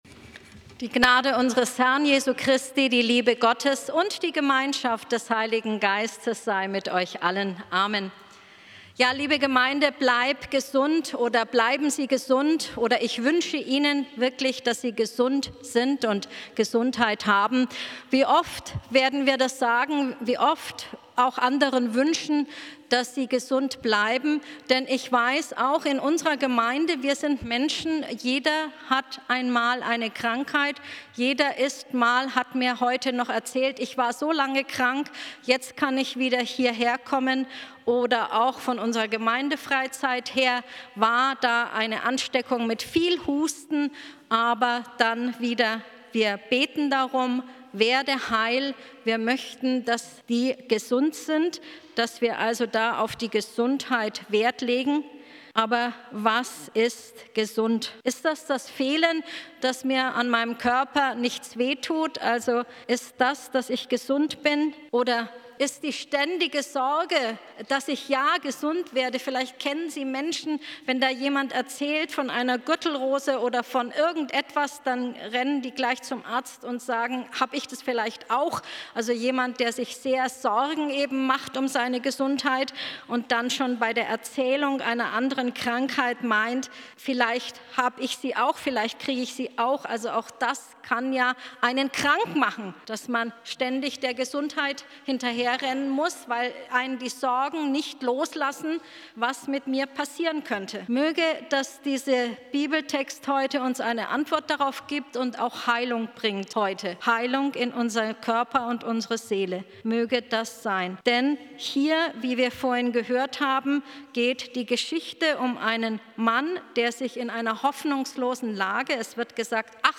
Predigt vom 12.10.2025 Spätgottesdienst